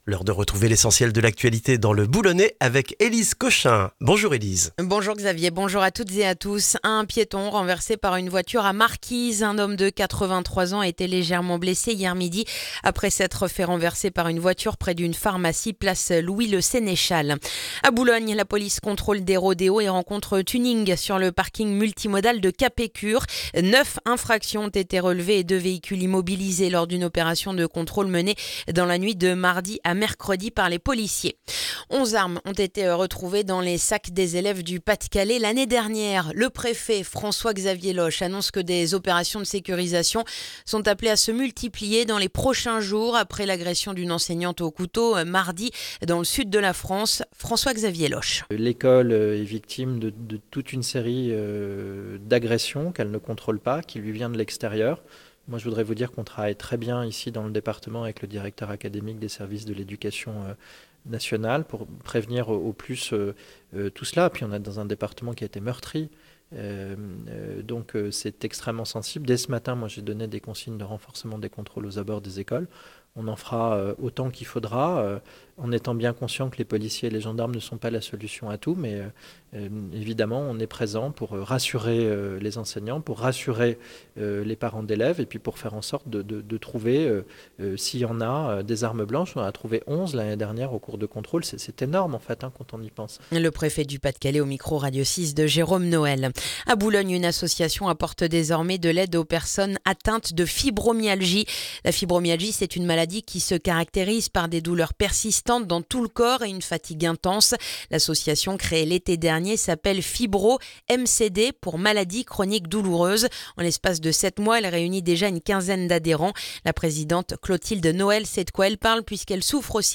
Le journal du jeudi 5 février dans le boulonnais